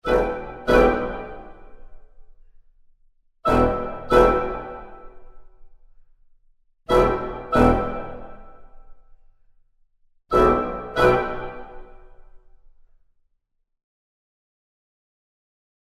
… the woodwinds section:
OrchestralHits_Woodwinds.mp3